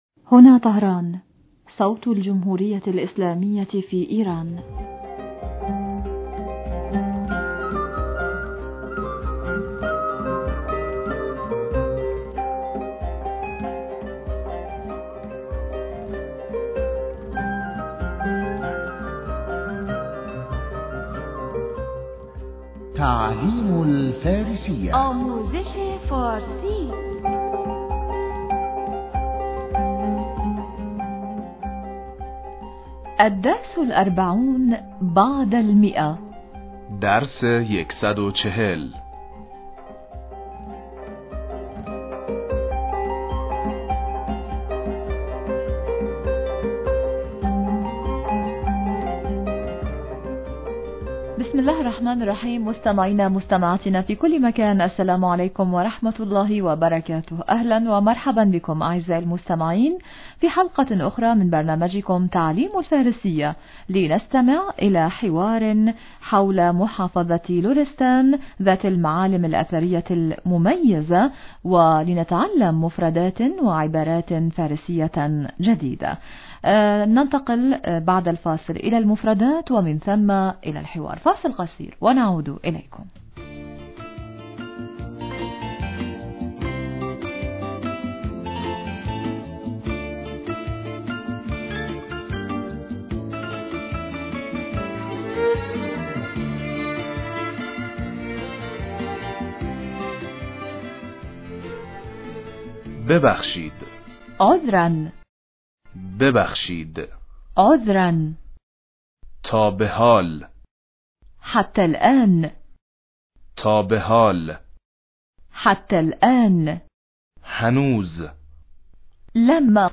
إذاعة طهران- تعلم الفارسية- بحث حول المعالم الاثرية في محافظة لرستان
لنستمع إلي حوار حول محافظة لرستان ذات المعالم الأثرية المميزة ولنتعلّم مفردات وعبارات فارسية جديدة.